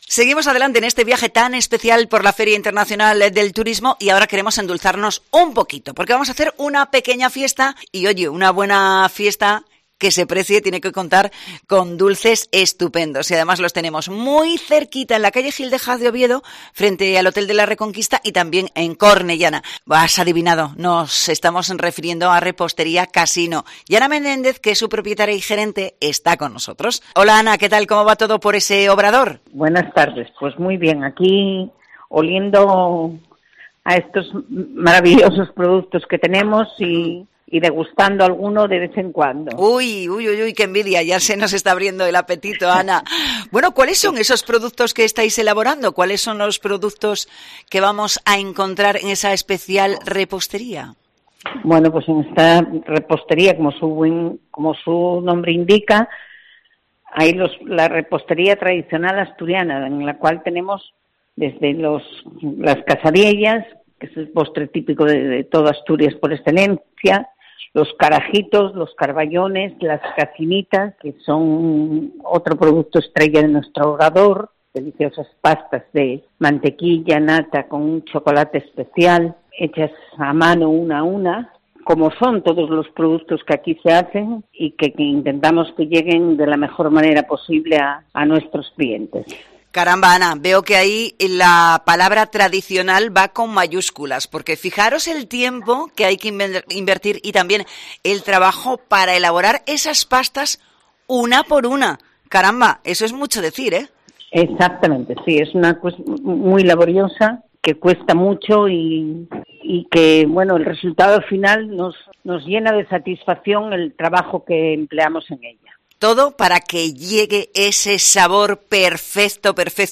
Repostería Casino, en Oviedo y Cornellana, endulza el programa especial de COPE Asturias desde IFEMA Madrid con motivo de Fitur, la Feria Internacional de Turismo
Entrevista